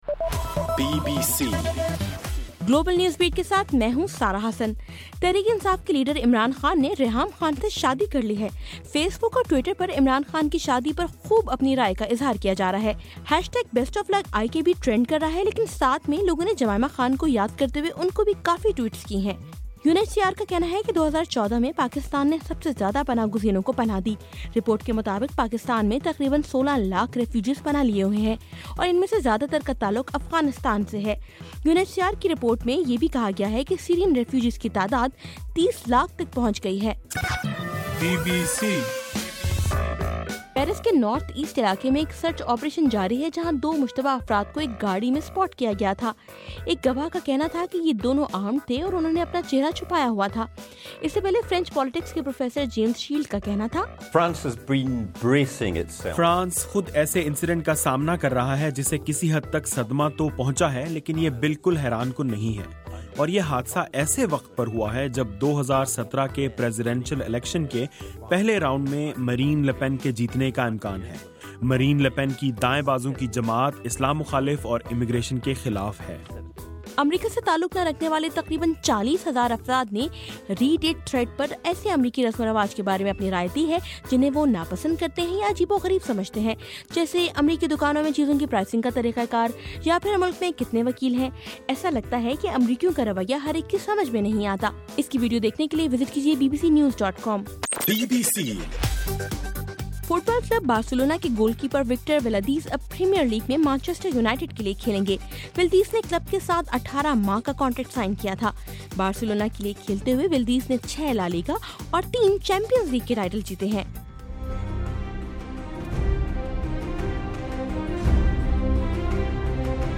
جنوری 8: رات 11 بجے کا گلوبل نیوز بیٹ بُلیٹن